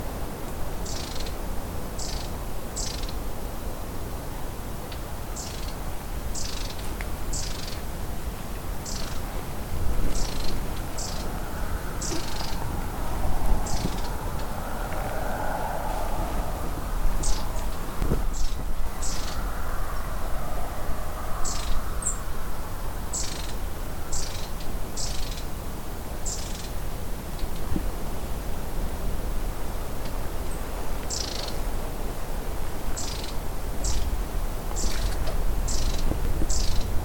Mésange à longue queue ou Orite à longue queue
On découvre cet oiseau très discret dans un arbre parce qu’il s’y déplace sans cesse d’une branche à l’autre en poussant de petits cris.
Les tsrriiih de la Mésange à longue queue – 24/01/2022 (on entend également des mésanges charbonnières)